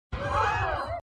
sfx_gasp.mp3